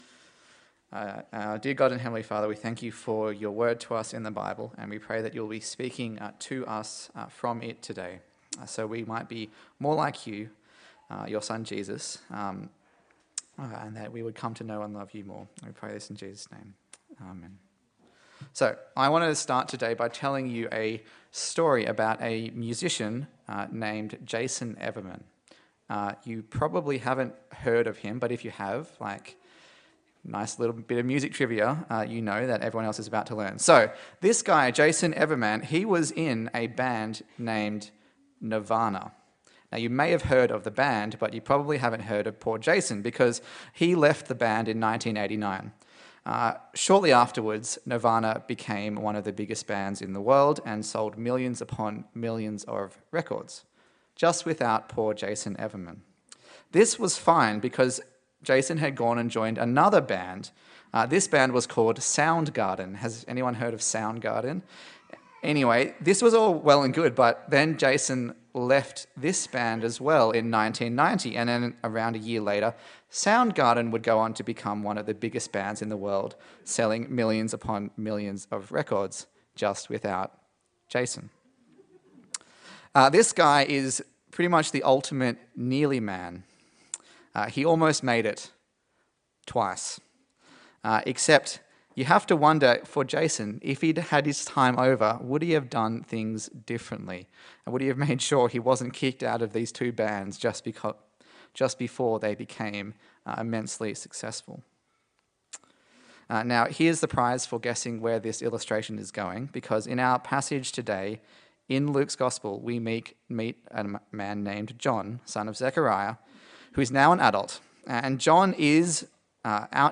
Luke Passage: Luke 3:1-20 Service Type: Sunday Service